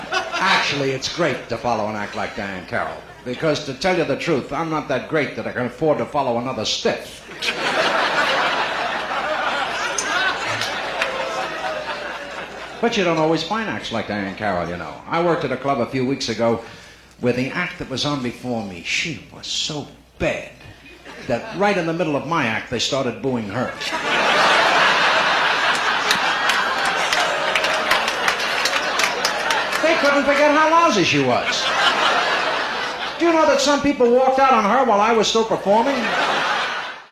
And here's a clip from the opening of Don Adams…Live?, a comedy record that the star of Get Smart recorded in Las Vegas in 1967…